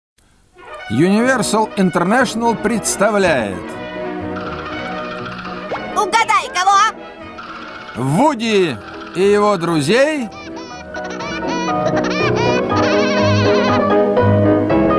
Звук заставки российского мультсериала